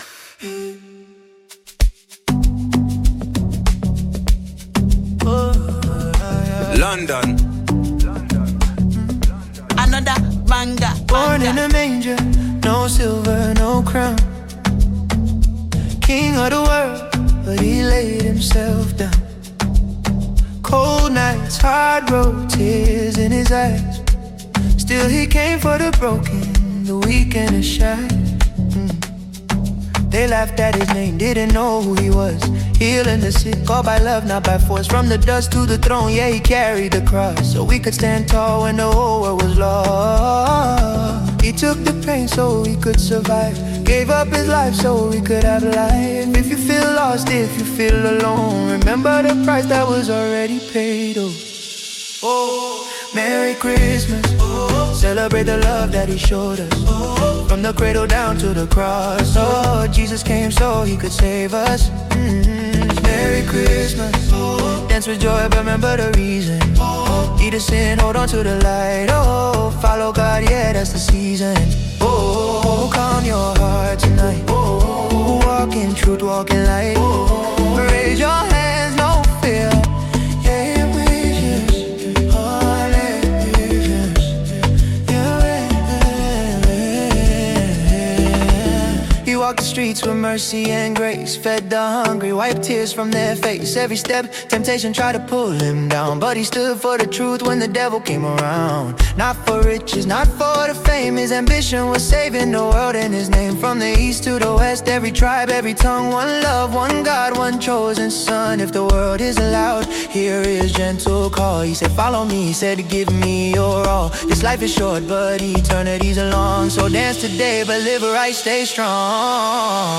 feel-good festive song
contemporary Afro-fusion style